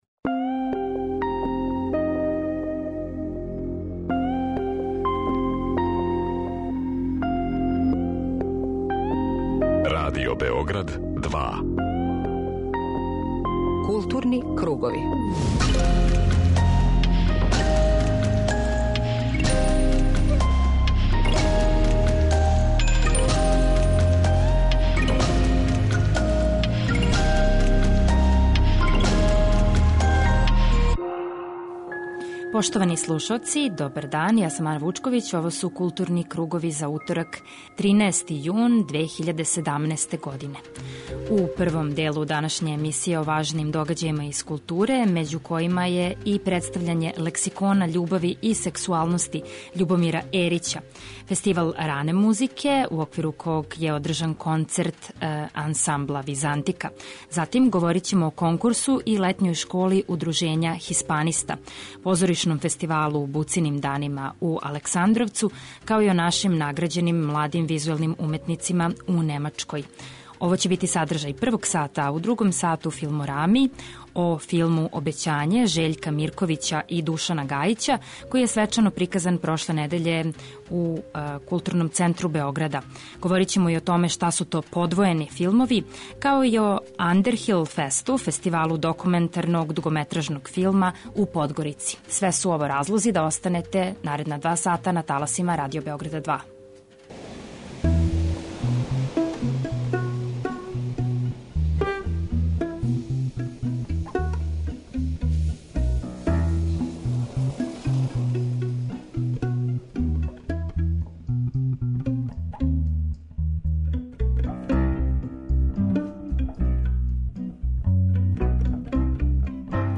О овом филму који нас води у Рогљево, где се праве добра вина, за данашњу емисију говоре аутори и актери.